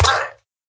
minecraft / sounds / mob / villager / death.ogg
death.ogg